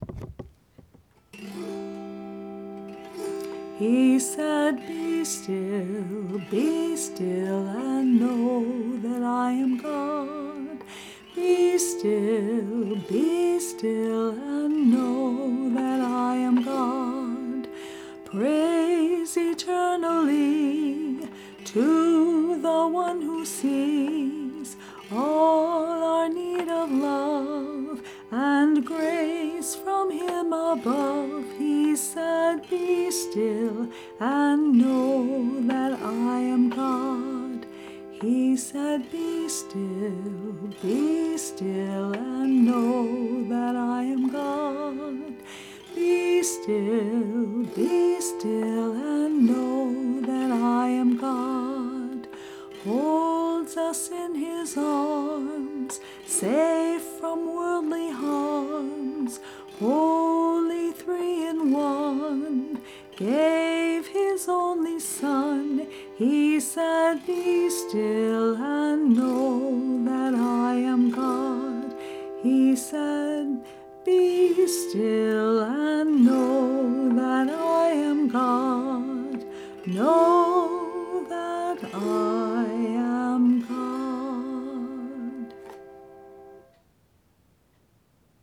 This entry was posted in Christian Music, Uncategorized and tagged , , , , , , , , .